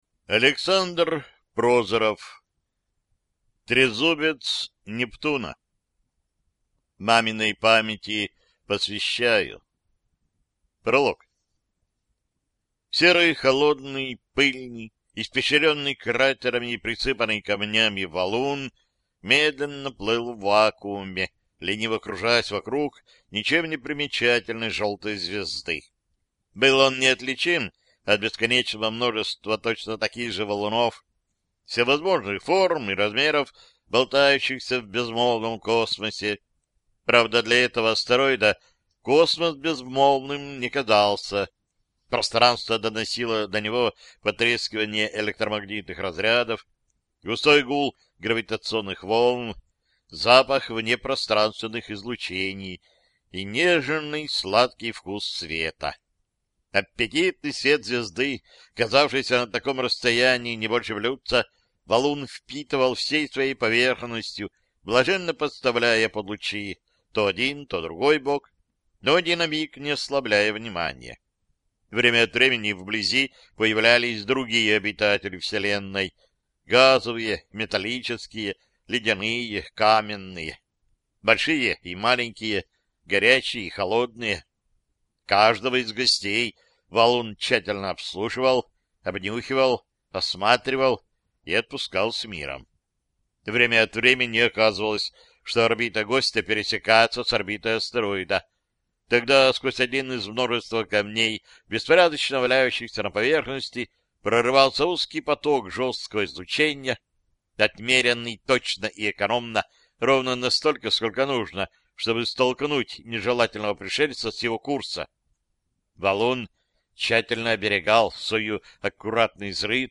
Аудиокнига Трезубец Нептуна | Библиотека аудиокниг